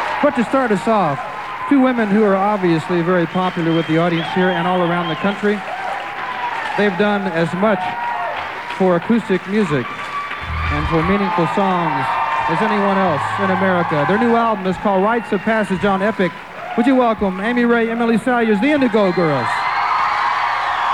01. introduction (0:20)